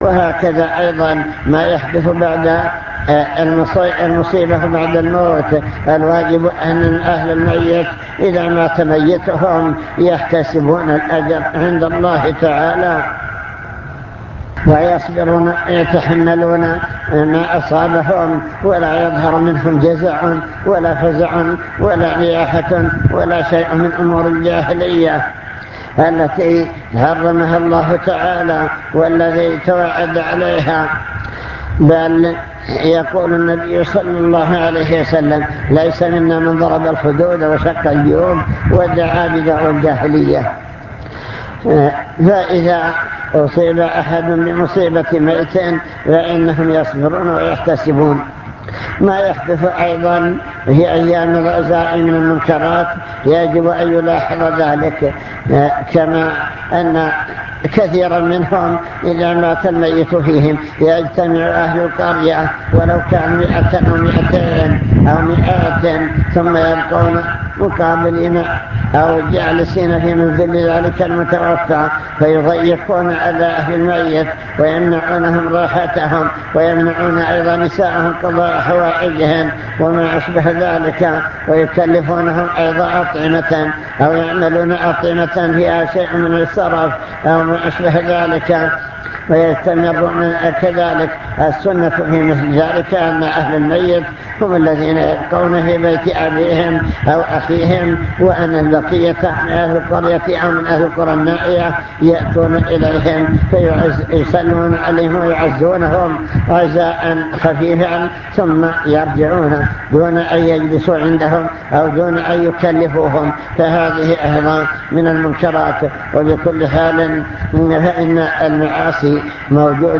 المكتبة الصوتية  تسجيلات - محاضرات ودروس  محاضرة بعنوان من يرد الله به خيرا يفقهه في الدين التحذير من بعض المنكرات